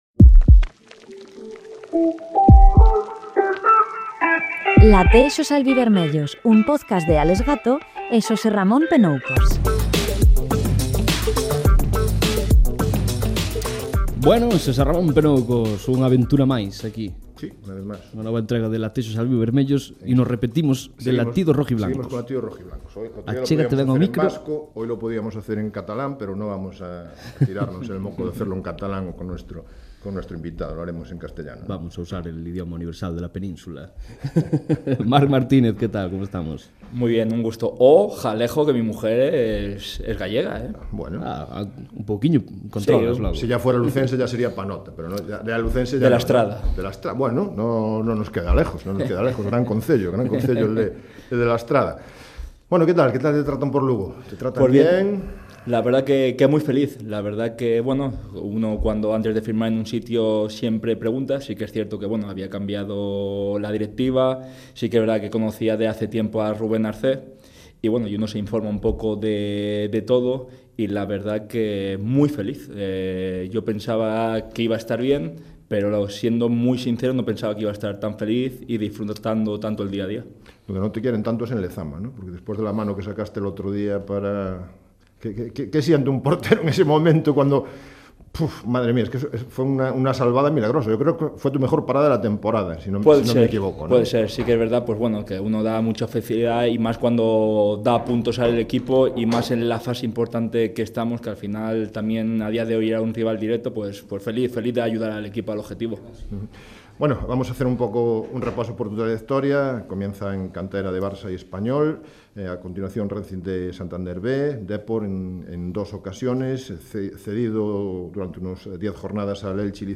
Falamos dos seus comezos baixo paus, do camiño que o levou ata o Anxo Carro e de como está afrontando unha tempada esixente, tanto no terreo de xogo como fóra del. Unha conversa pausada, sincera e chea de fútbol e personalidade.